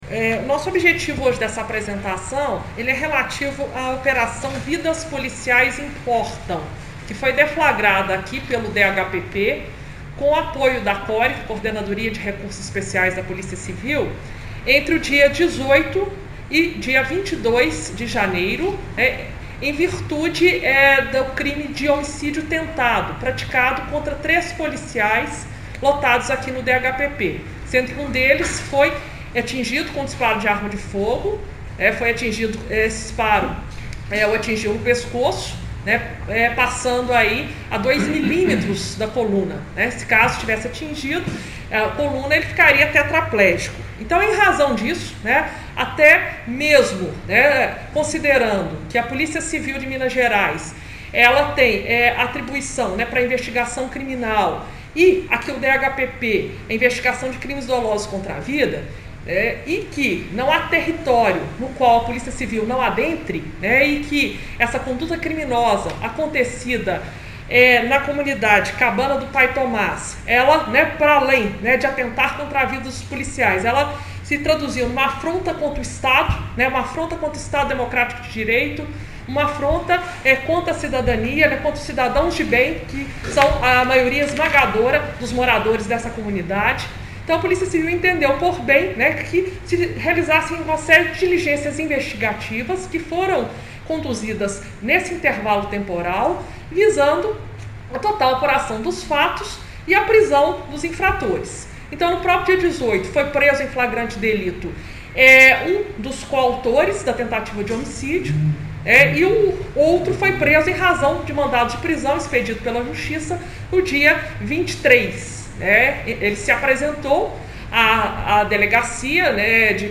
Coletiva-DHPP.mp3